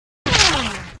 bullet.ogg